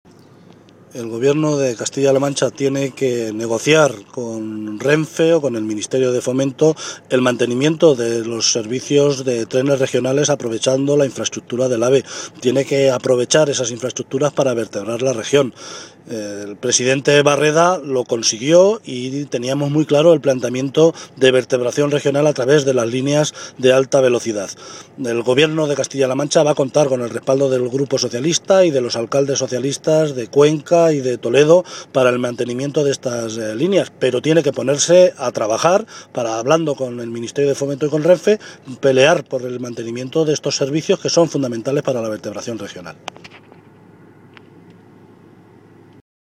Cortes de audio de la rueda de prensa
Martinez-Guijarro-AVE.mp3